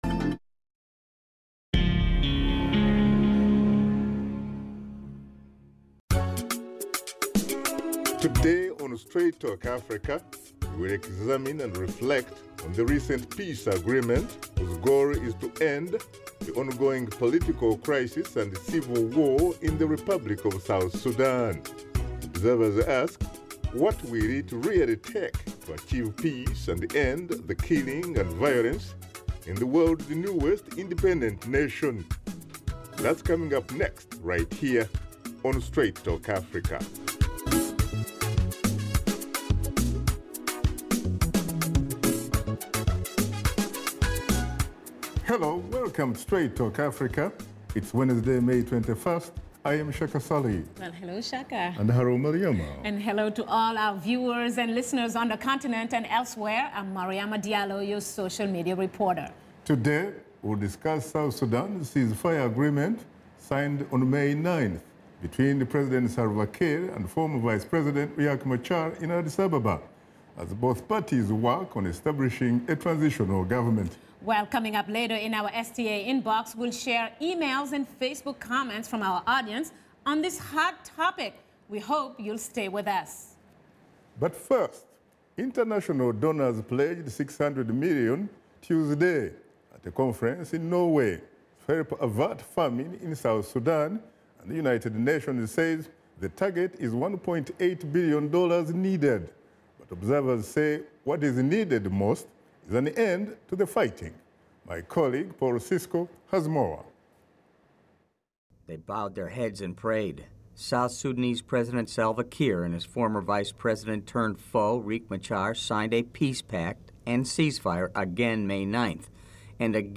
Host Shaka Ssali and his guests discuss the recent ceasefire agreement in South Sudan.